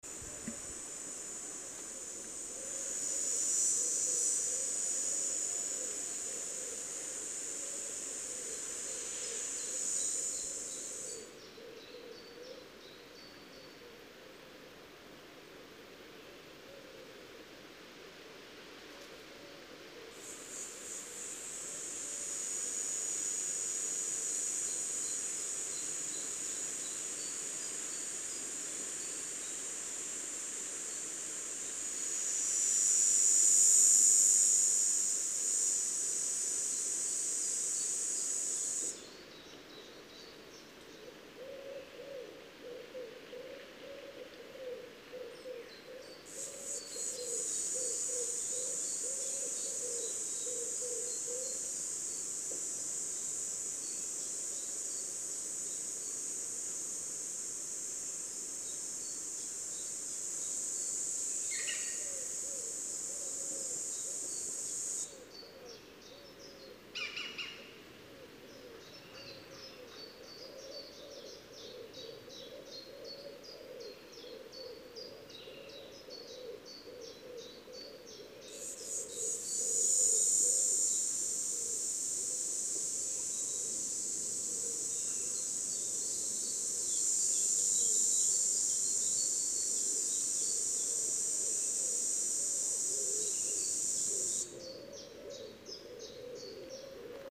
Cigale grise Cicada orni
dans un frêne à proximité du fleuve Hérault (d'où les cris d'enfants !)